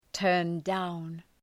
turn-down.mp3